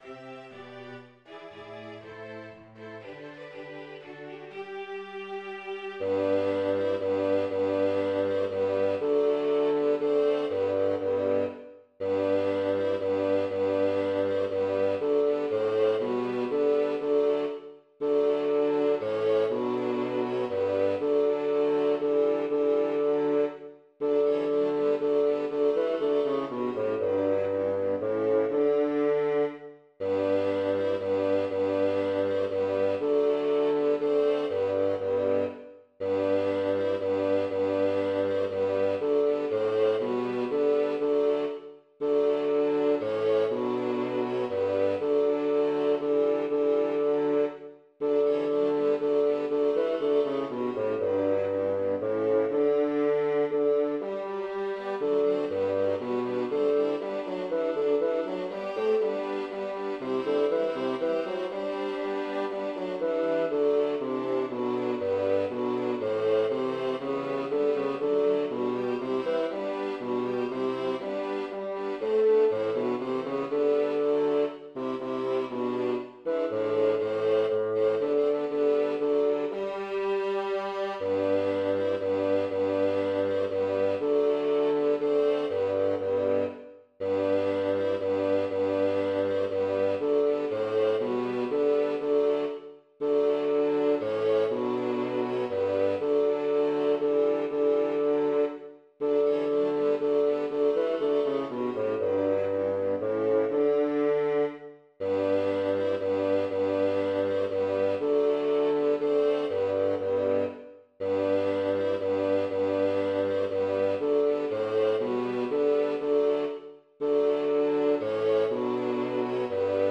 Schneeberger Weihnachtslied Bass 2 als Mp3
Schneeberger-Weihnachtslied-Bass-2.mp3